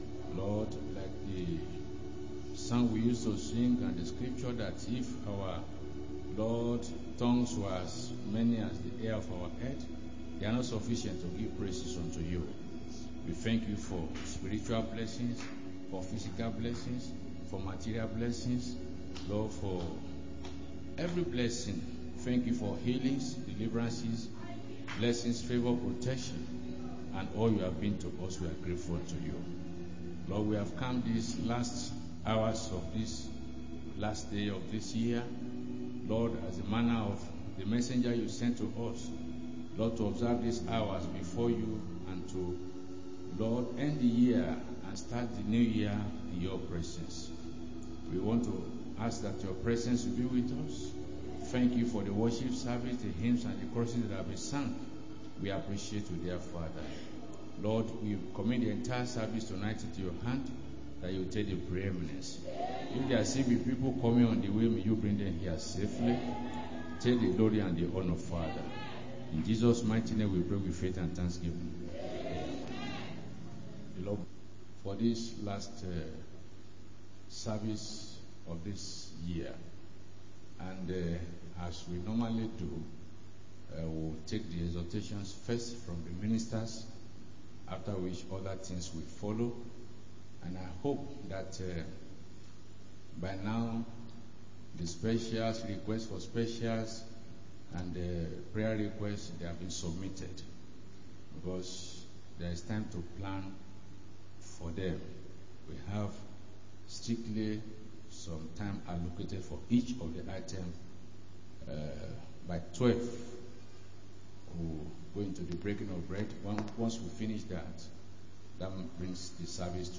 2025 Cross-Over Service 31-12-25